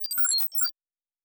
Sci-Fi Sounds / Electric / Data Calculating 1_1.wav
Data Calculating 1_1.wav